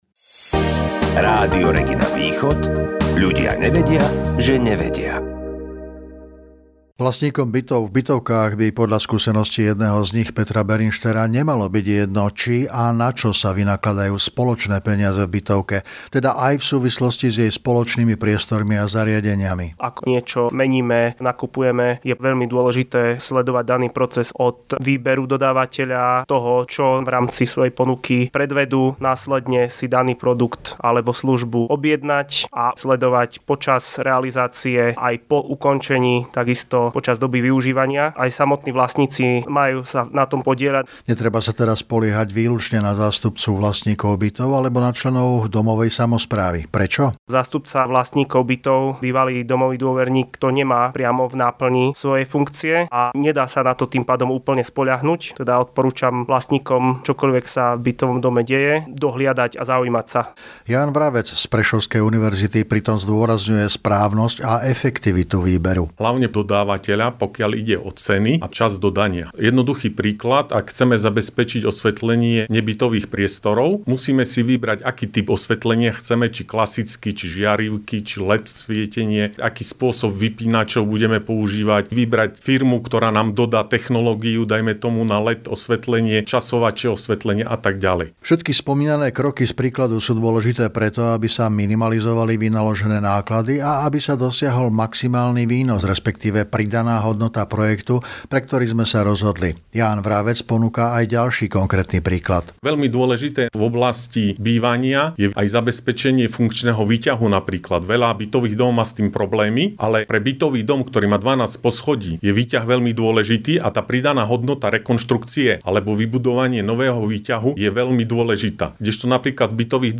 Nahrávanie rozhlasových relácií